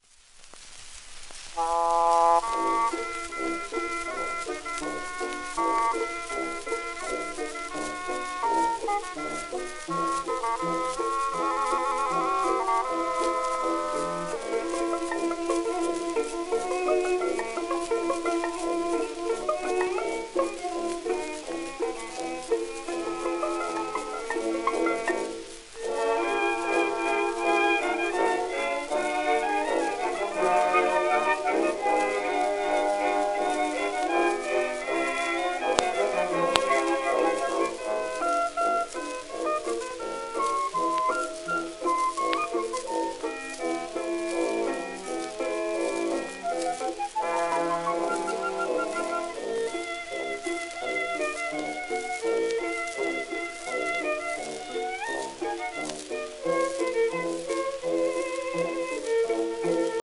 1916年録音